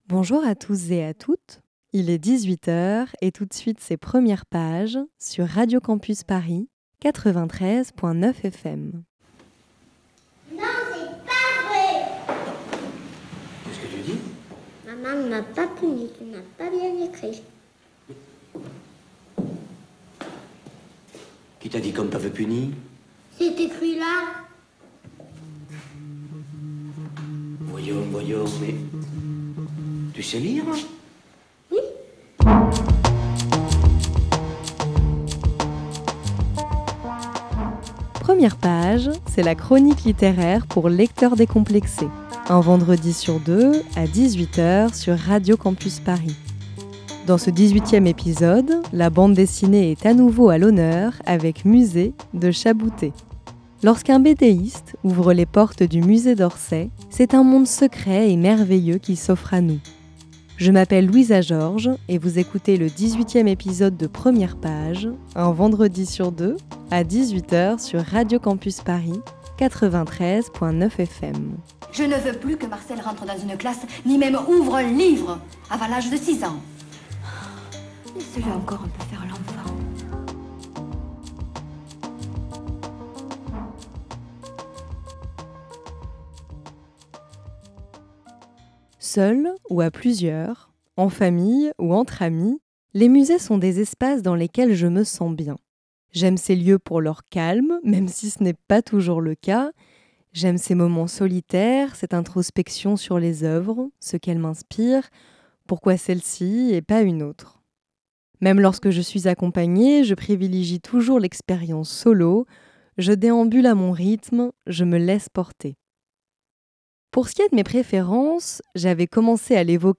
Voici en podcast le dix-huitième épisode de Premières pages, une chronique littéraire pour lecteur.trice.s décomplexé.e.s, un vendredi sur deux sur Radio Campus Paris.